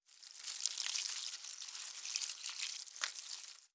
SFX_WateringPlants_01.wav